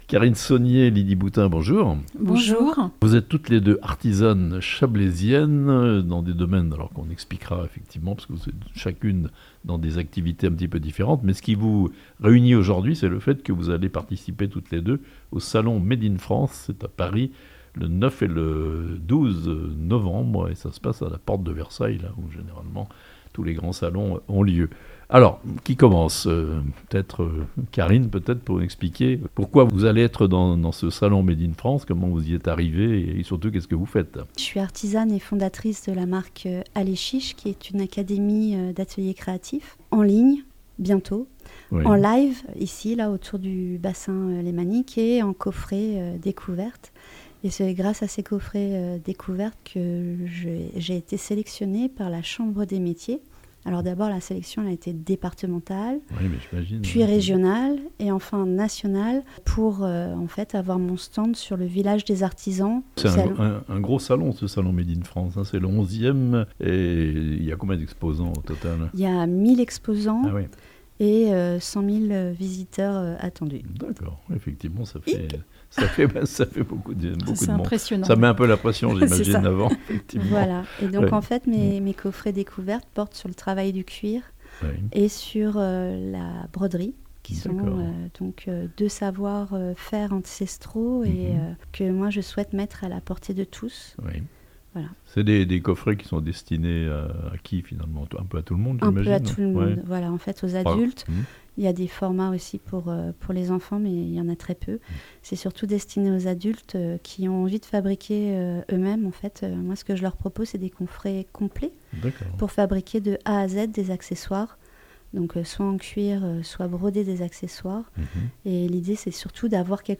Le Chablais bien présent au salon "Made in France" à Paris (interviews)